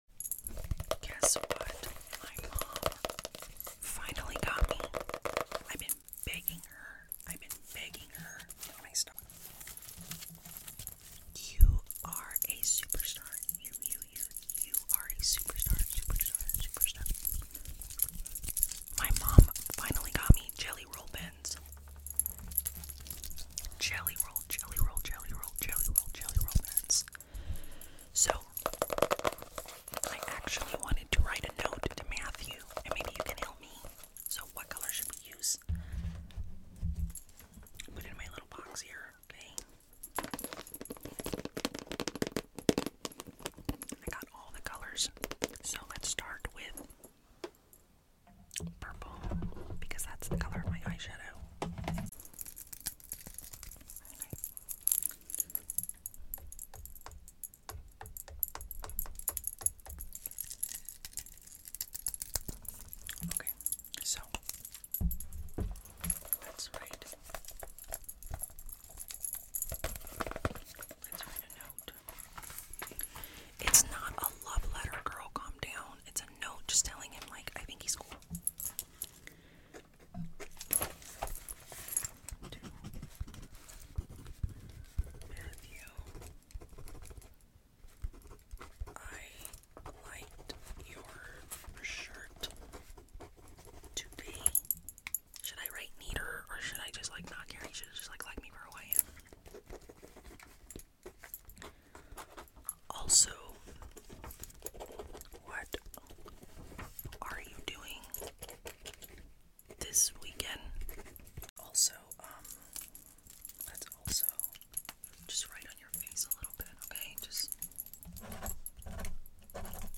Y2K ASMR: Gel pens + sound effects free download
Y2K ASMR: Gel pens + notebook tapping = ultimate nostalgic tingles”